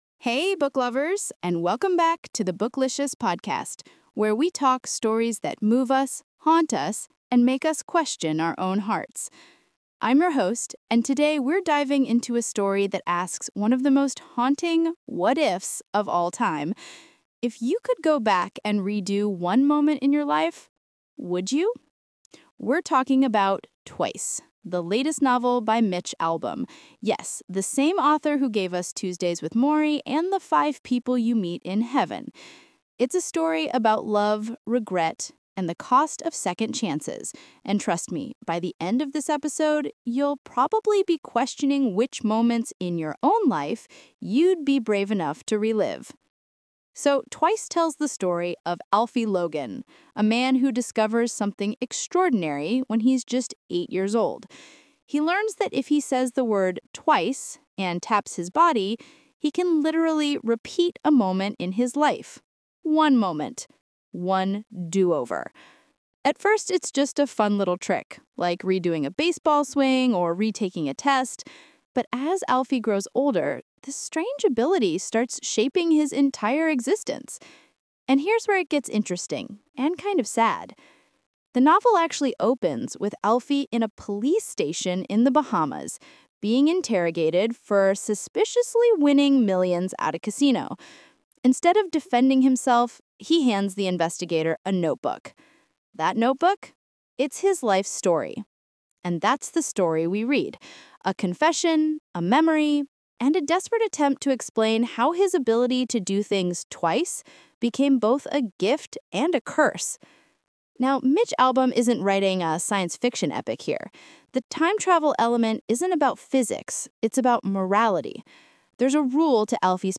Twice: By Mitch Albom | Book Review Podcast